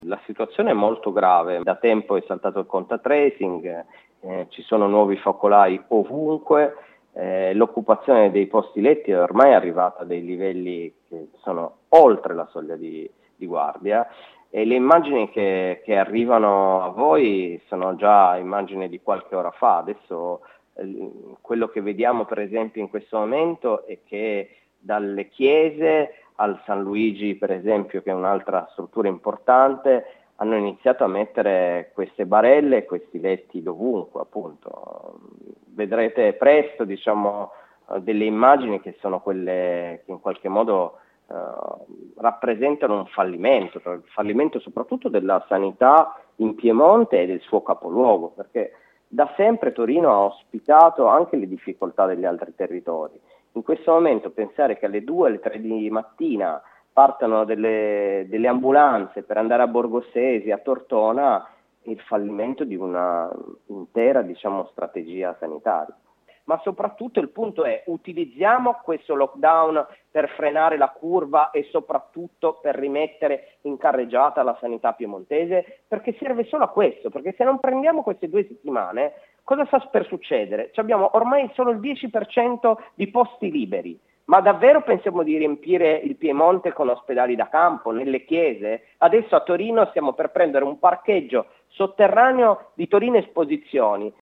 Sono la fotografia esatta di quello che sta succedendo”, denuncia ai nostri microfoni il capogruppo di Liberi Uguali e Verdi al consiglio regionale del Piemonte Marco Grimaldi.